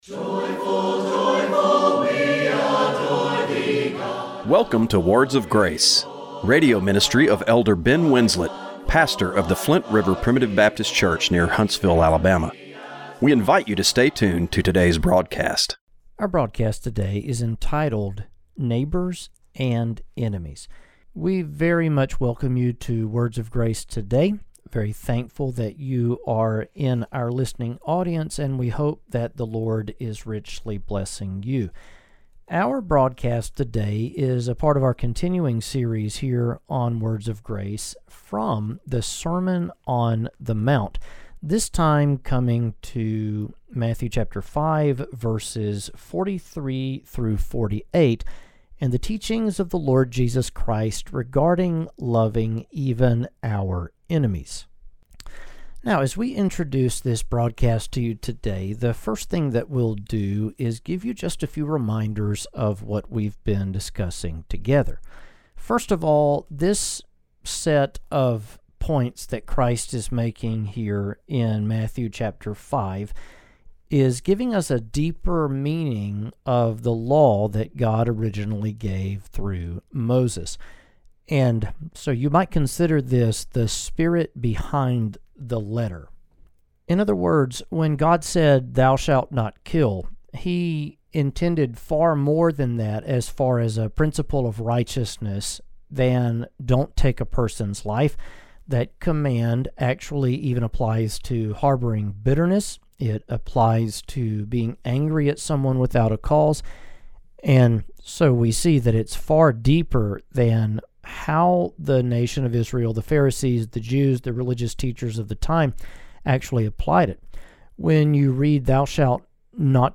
Radio broadcast for July 6, 2025.